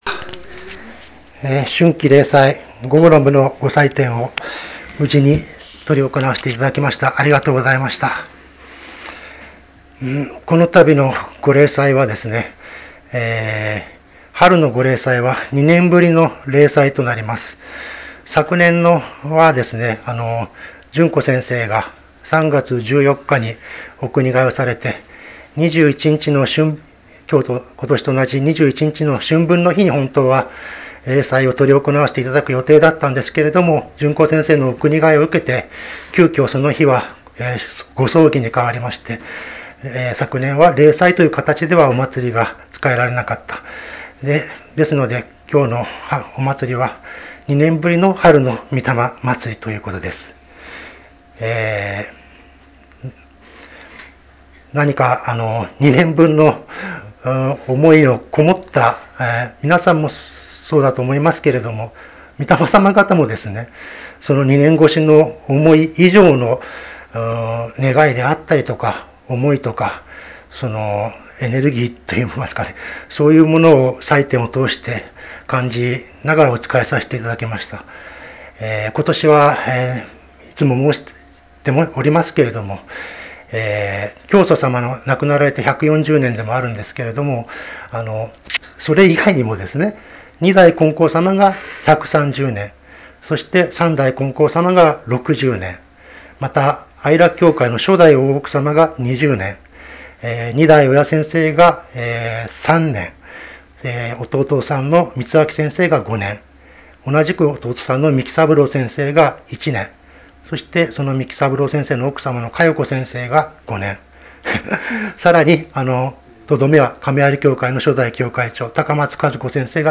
春季霊祭教話